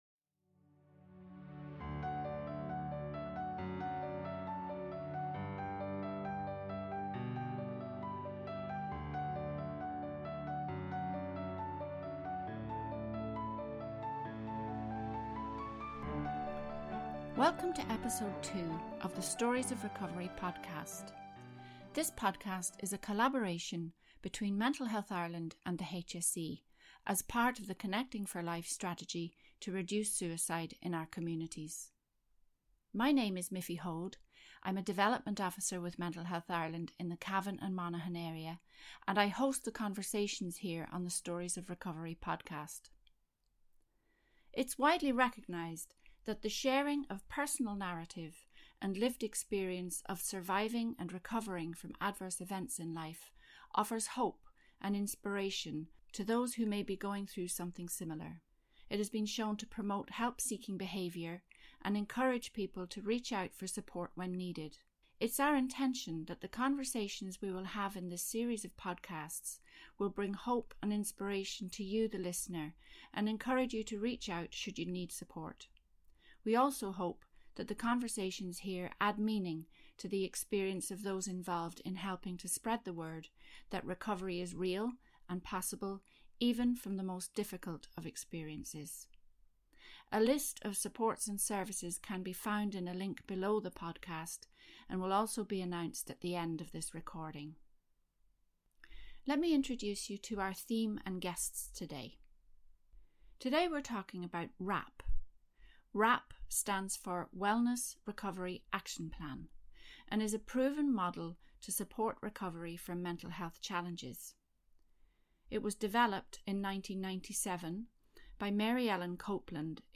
The podcast series will hear from local people who have lived through adversities that challenged their mental health and wellbeing and how they now flourish and continue to stay well.